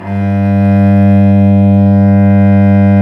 Index of /90_sSampleCDs/Roland L-CD702/VOL-1/STR_Vc Arco Solo/STR_Vc Arco p nv